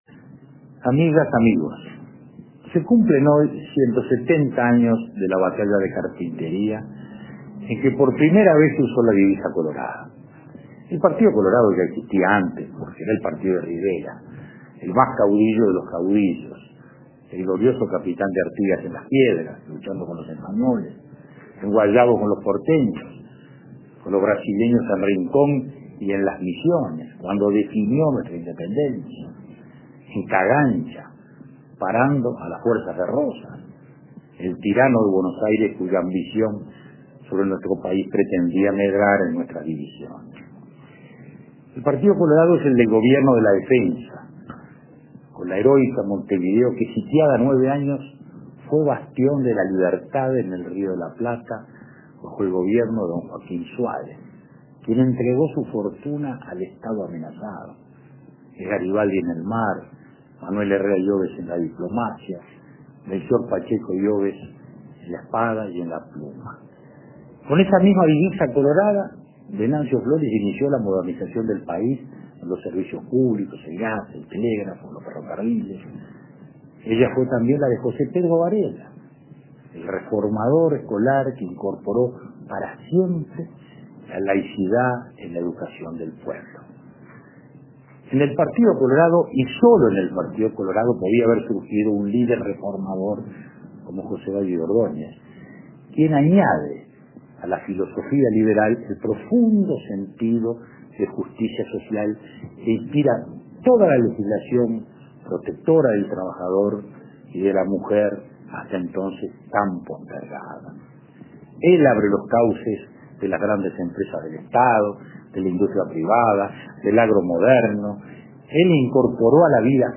Alocución del ex presidente Julio María Sanguinetti a propósito del 170 aniversario de la Batalla de Carpintería